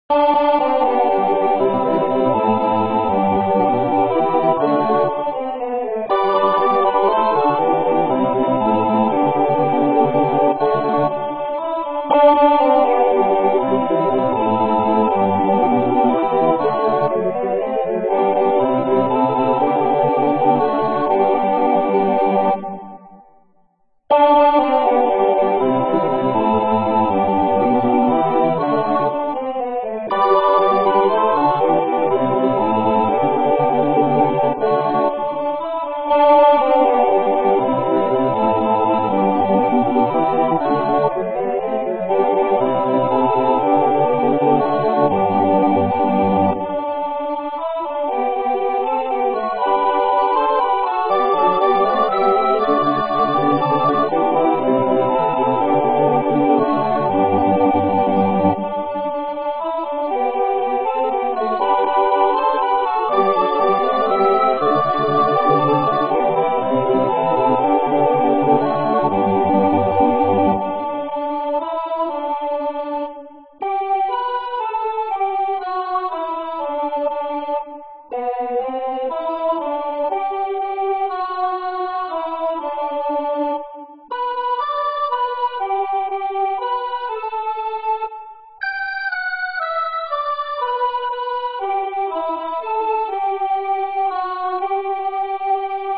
Makundi Nyimbo: Anthem | Mafundisho / Tafakari